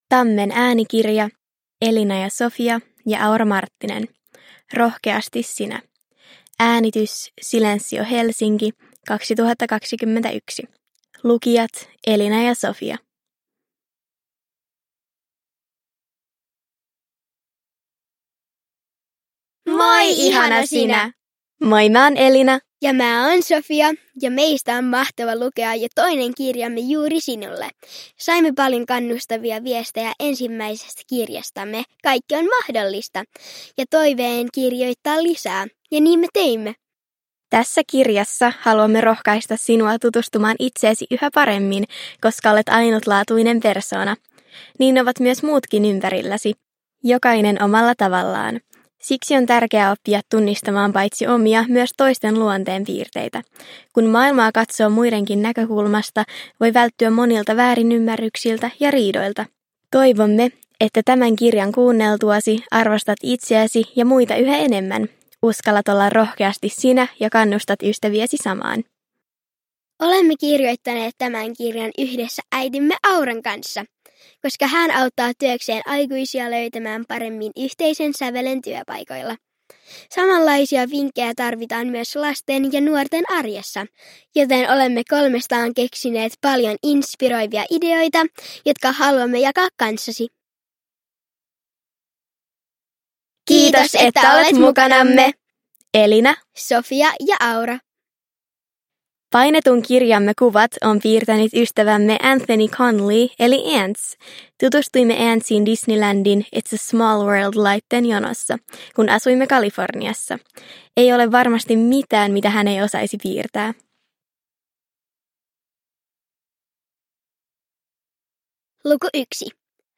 – Ljudbok – Laddas ner